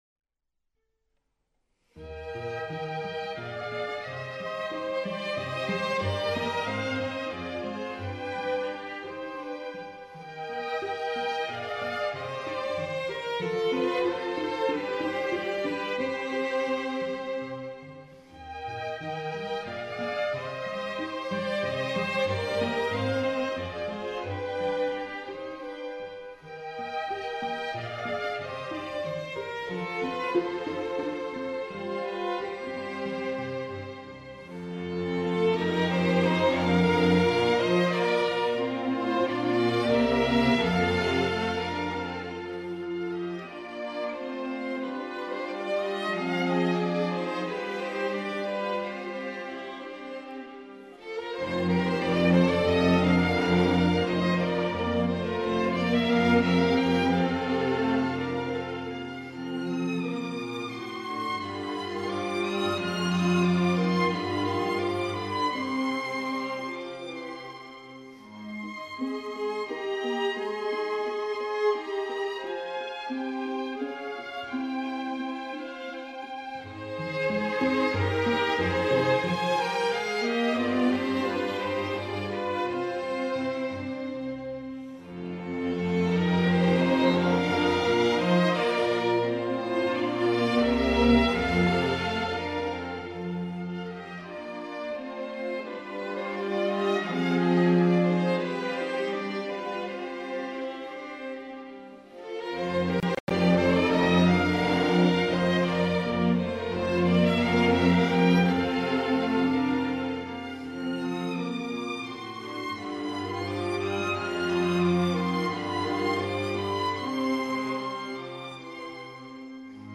Repertoár souboru obsahuje skladby od baroka až po současnost, které odpovídají zvukovým možnostem dvanáctičlenného smyčcového orchestru, dnes však už není výjimkou jeho rozšíření o další nástroje.